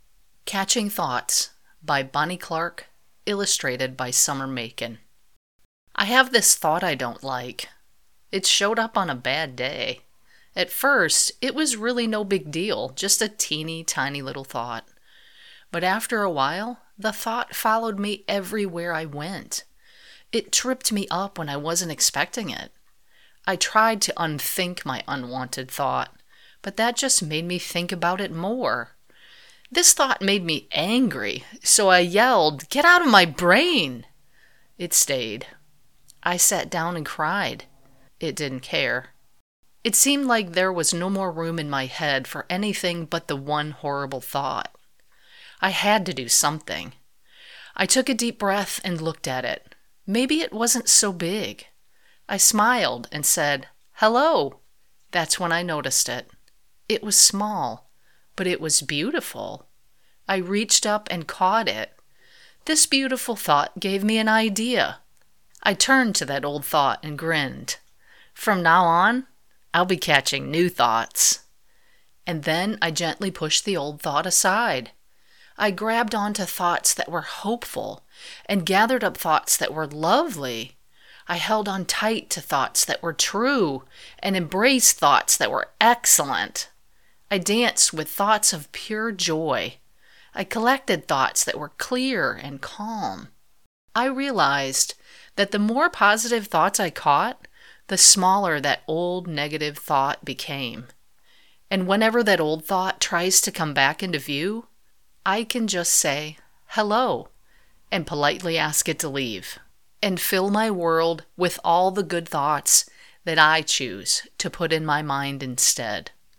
children's book reading